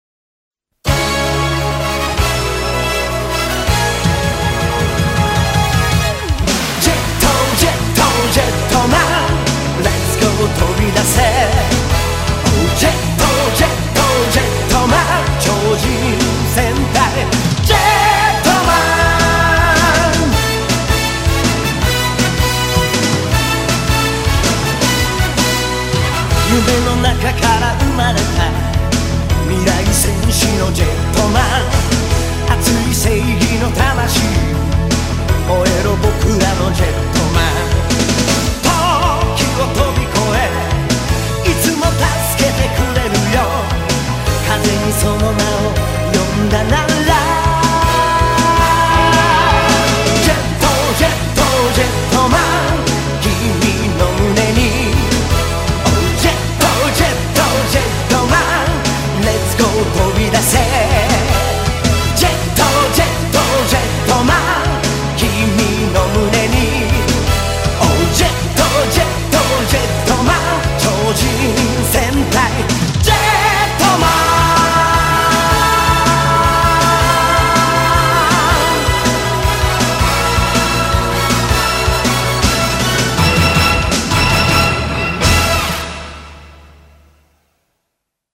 BPM160
Audio QualityPerfect (High Quality)
Horribly catchy with some great 90s synths and guitars.